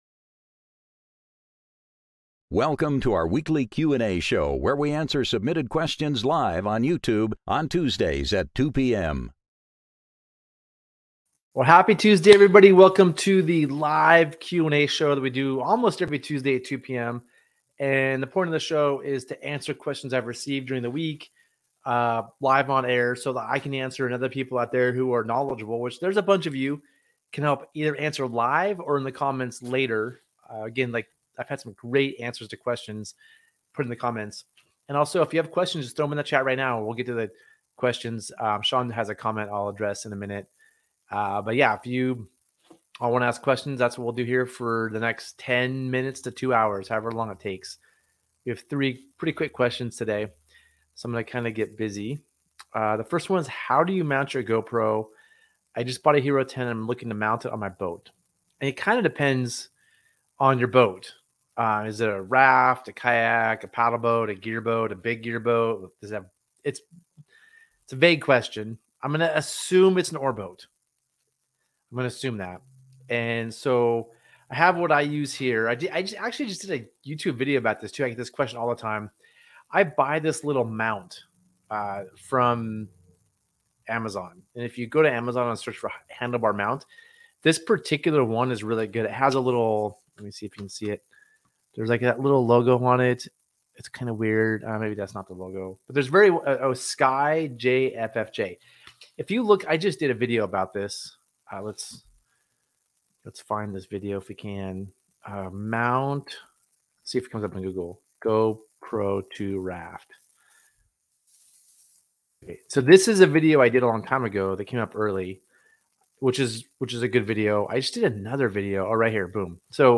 Gear Garage Live Show Gear Garage Q & A Tuesday Show | October 10th, 2023 Oct 13 2023 | 00:48:13 1x 00:00 / 00:48:13 Subscribe Share Spotify RSS Feed Share Link Embed